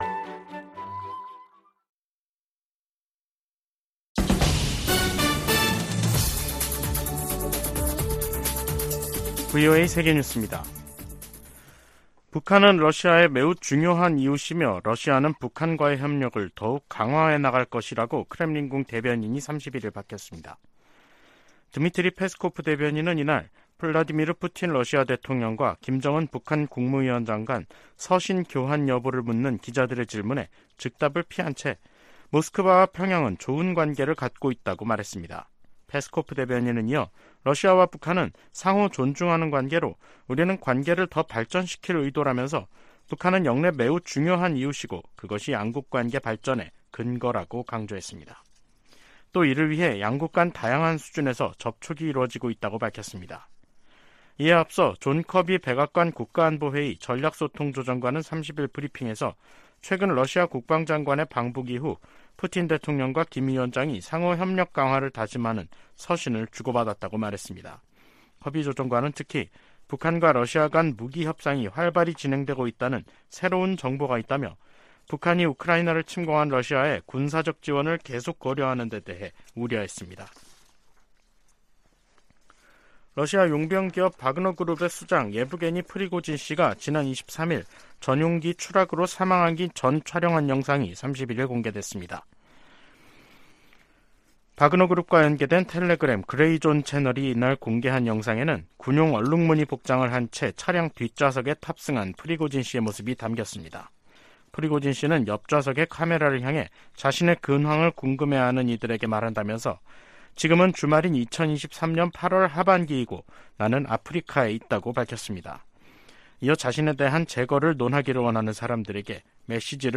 VOA 한국어 간판 뉴스 프로그램 '뉴스 투데이', 2023년 8월 31일 3부 방송입니다. 북한이 미한 연합훈련에 반발해 동해상으로 탄도미사일을 발사 했습니다.